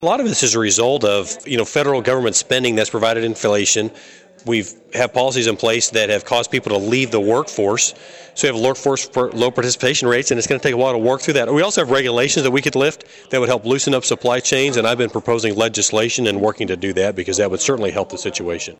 During a visit with local constituents at the Lyon County History Center Mann addressed a number of both foreign and domestic topics.